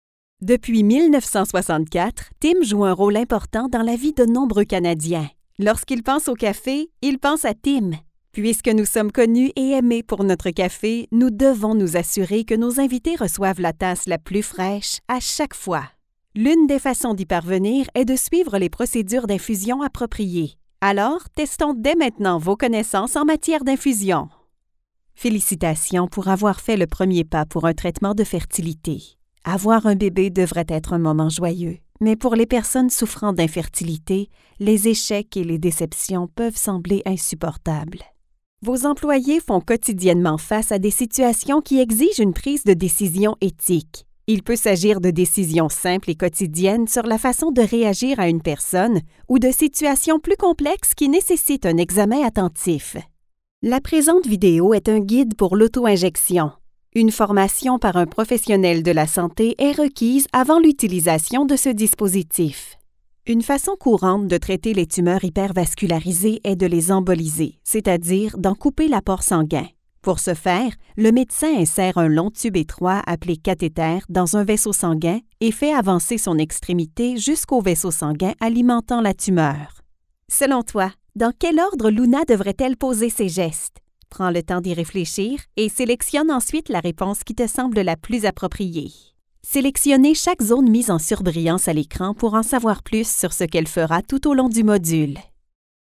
French Canadian eLearning Demo
French Canadian, Quebec French, neutral French
Young Adult
Middle Aged